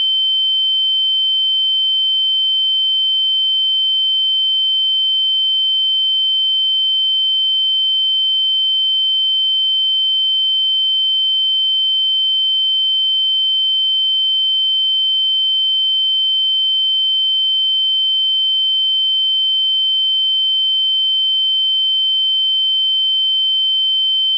ringing.ogg